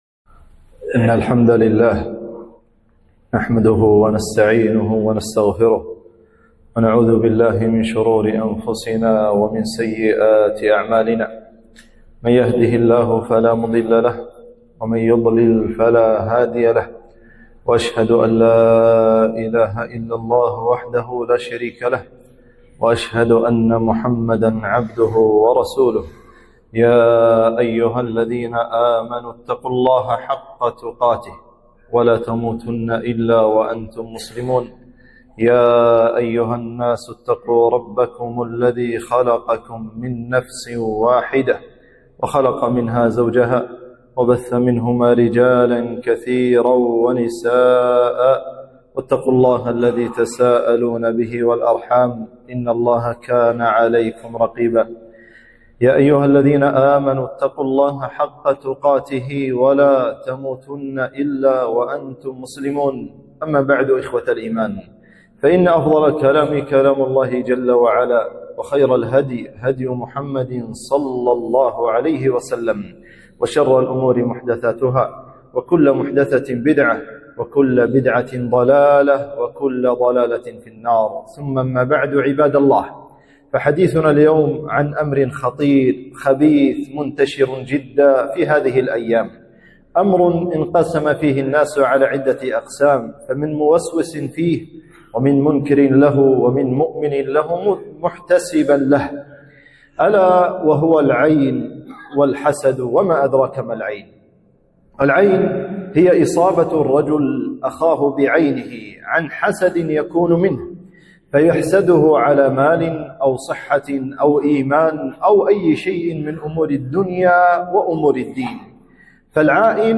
خطبة - خطر العين